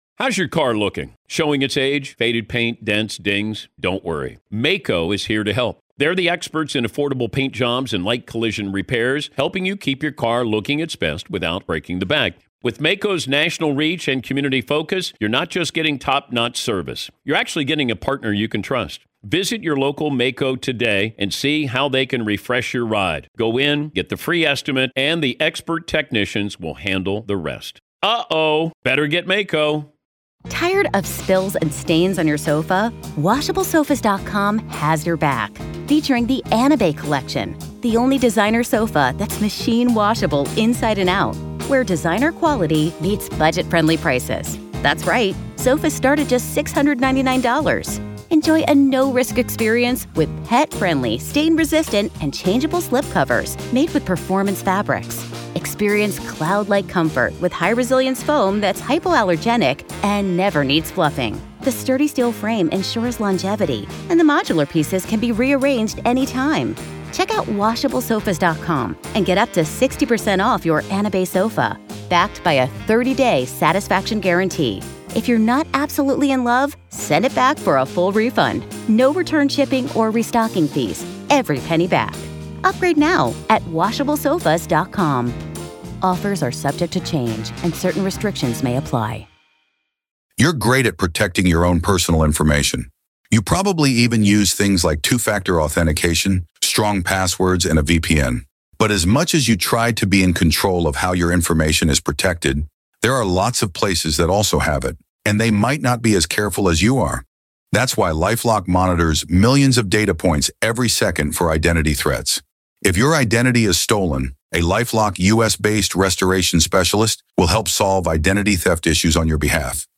As her third attempt at parole approaches, the discussion gets heated about the implications of releasing someone who has committed such a heinous act.